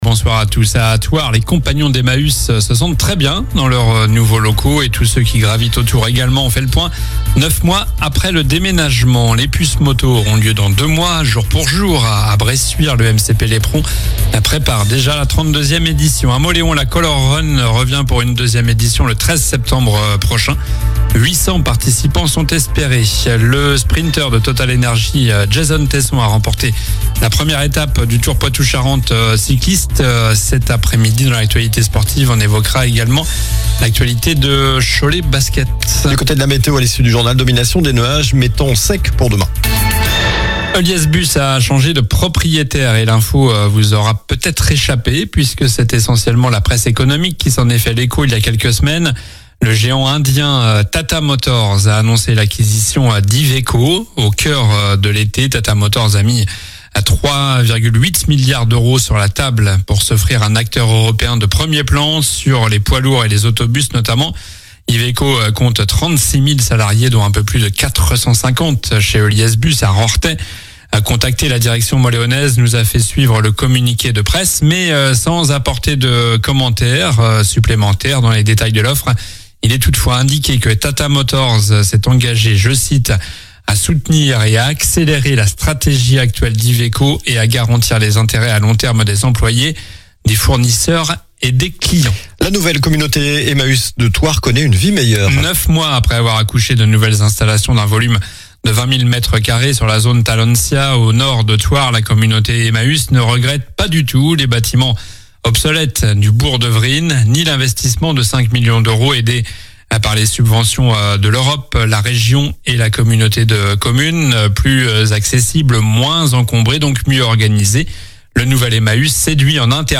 Journal du mardi 26 août (soir)